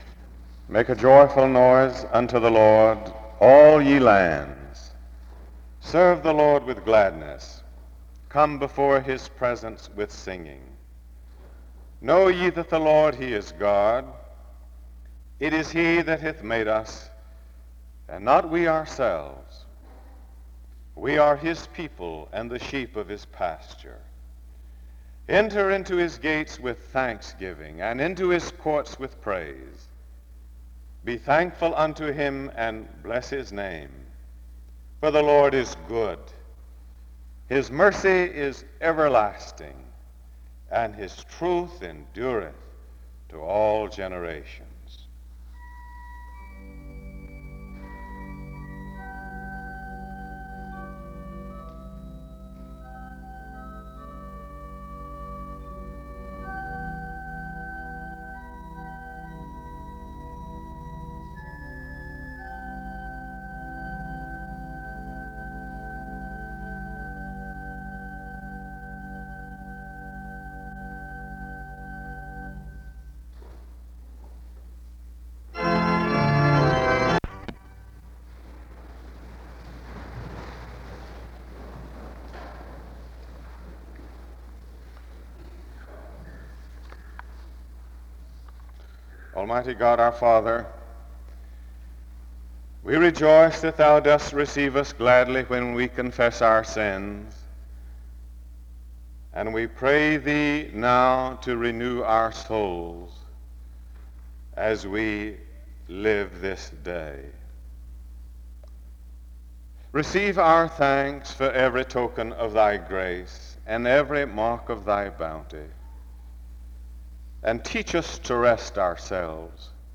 The service begins with a scripture reading (0:00-0:44). After which, there is a time of instrumental music (0:45-1:18). The speaker offers another prayer (1:19-3:01). Afterwards, a responsive reading is read (3:02-3:32).
The service closes in music and prayer (15:29-16:02).